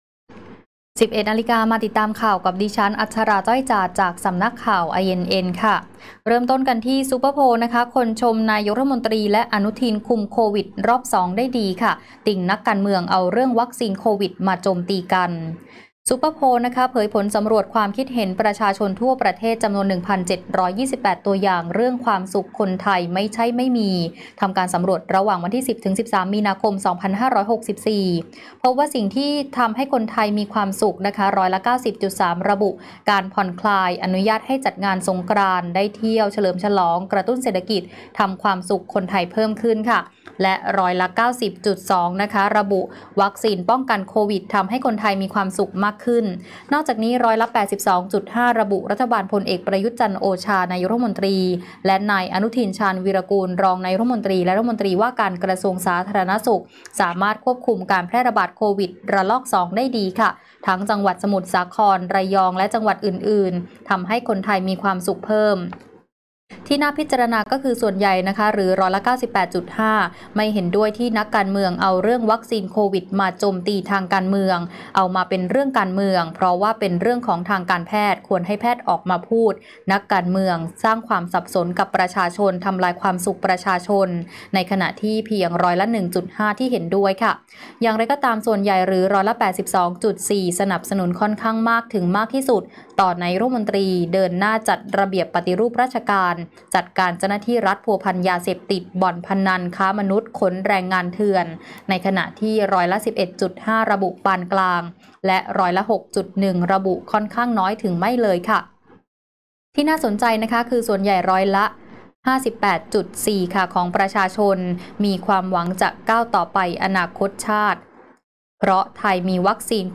ข่าวต้นชั่วโมง 11.00 น.